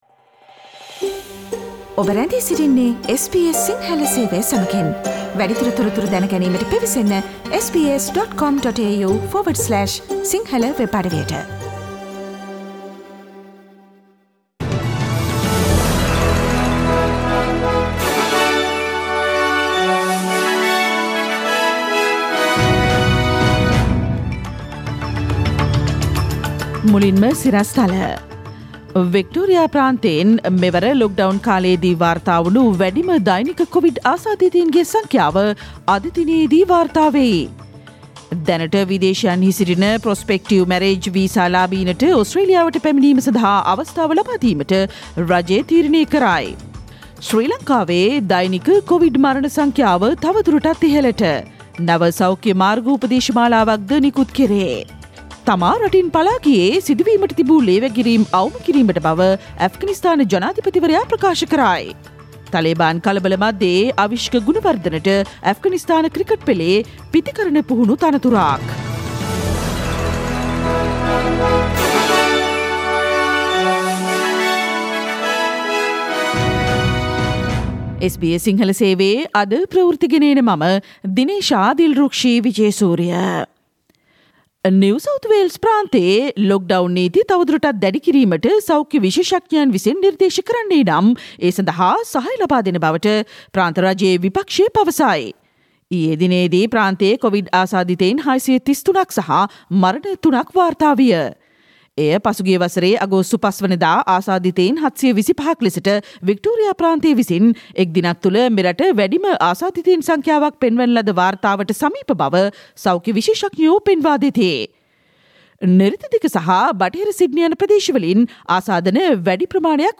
ඕස්ට්‍රේලියාවේ, ශ්‍රී ලංකාවේ අලුත්ම පුවත්, විදෙස් තොරතුරු සහ ක්‍රීඩා පුවත් රැගත් SBS සිංහල සේවයේ 2021 අගෝස්තු මස 19 වන බ්‍රහස්පතින්දා වැඩසටහනේ ප්‍රවෘත්ති ප්‍රකාශයට සවන්දෙන්න ඉහත චායාරූපය මත ඇති speaker සලකුණ මත click කරන්න.